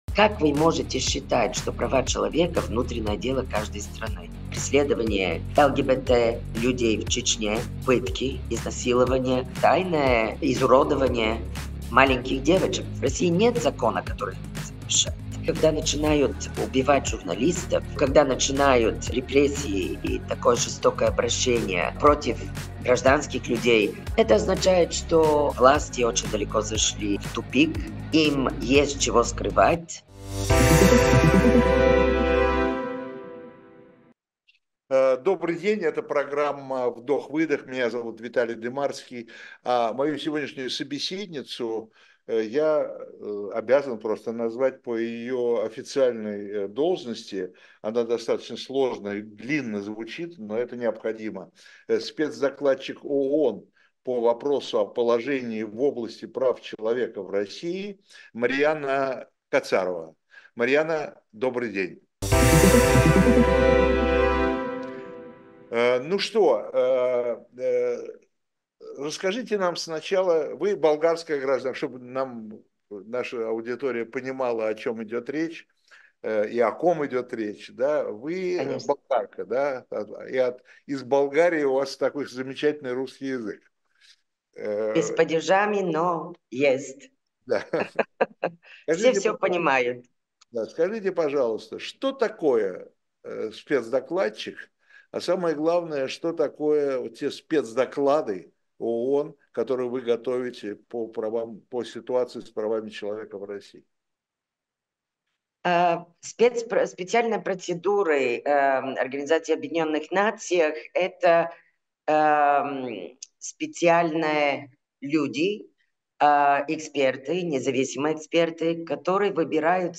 Эфир ведёт Виталий Дымарский